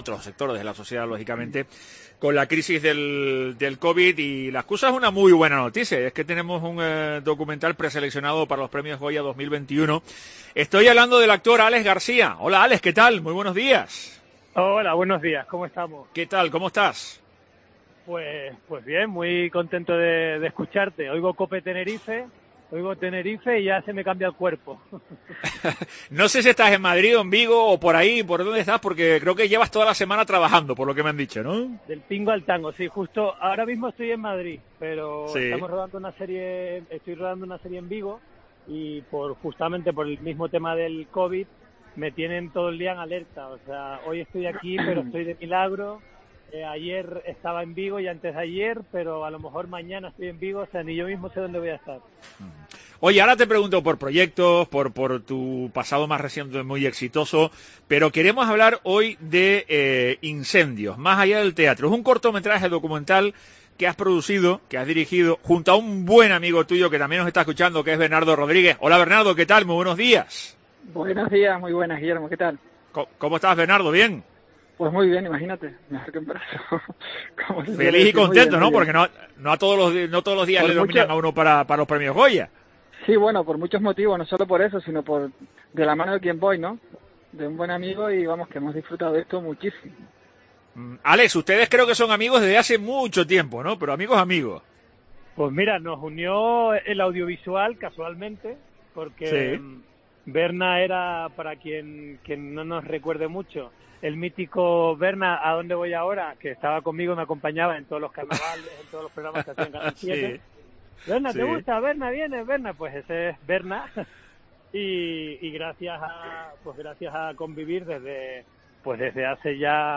Los dos protagonistas han compartido sus sensaciones al respecto de esta nominación en La Mañana de COPE Tenerife, esperando que el próximo 11 de enero, haya una buena noticia para la cultura en nuestra isla.